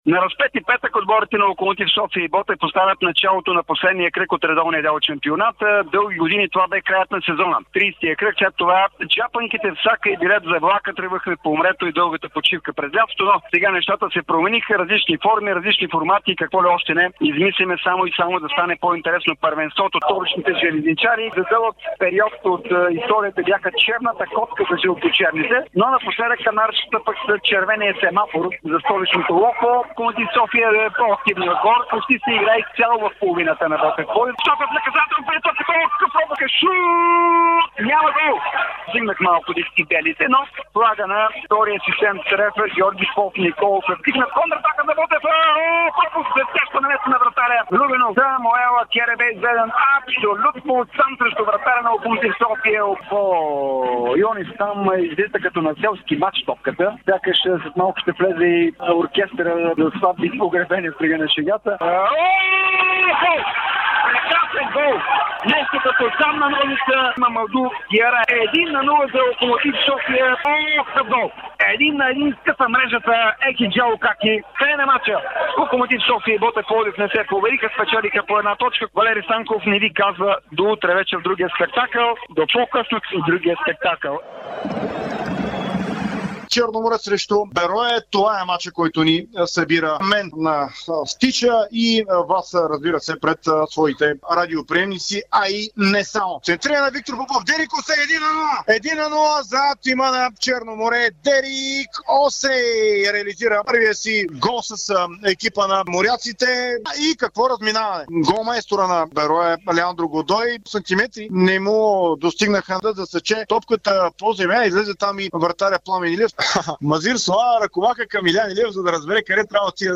През очите на коментаторите на Дарик, какво чухмеот родните футболни терени от последния 30-и кръг за редовния сезон в efbet Лига, който ни предложи голове, картони, фенерчета по трибуните и скандирания за "Оставка" от феновете на Левски и ЦСКА.